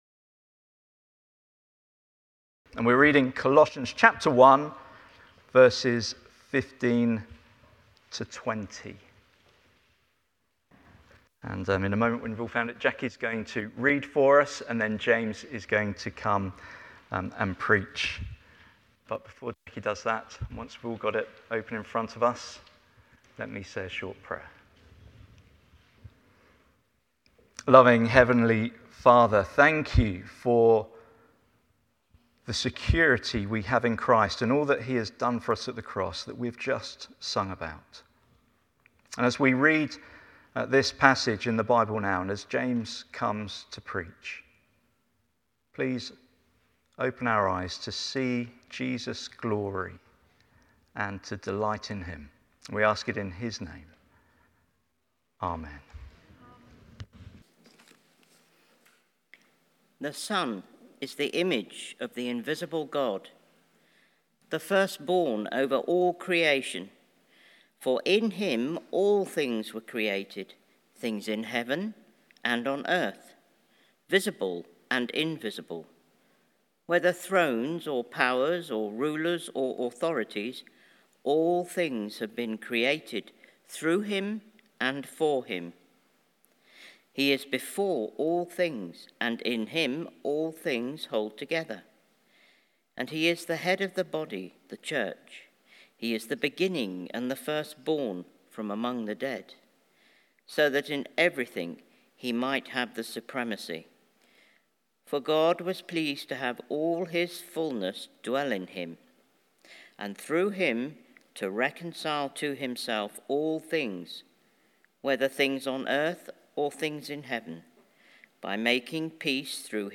Series: One-Off Sermons Passage: Colossians 1:15-20 Service Type: Sunday Morning Study Questions « Seeking What’s Lost Church Weekend 2025